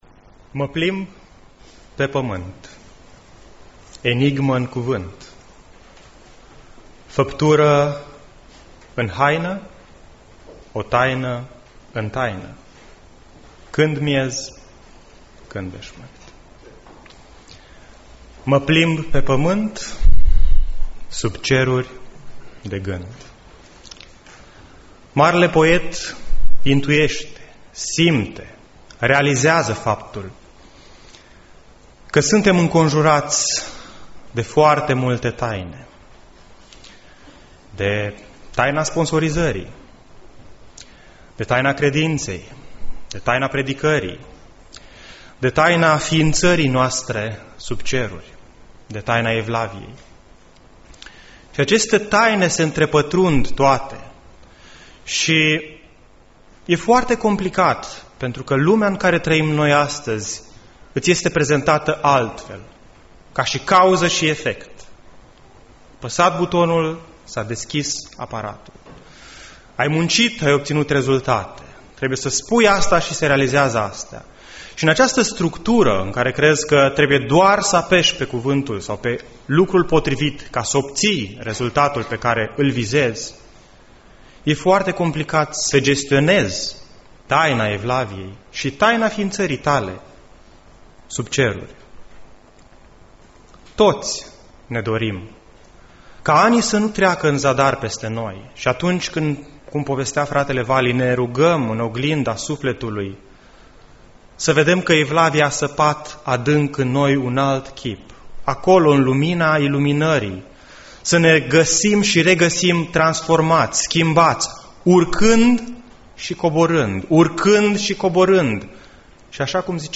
Predica Aplicatie 1 Timotei 6 1-2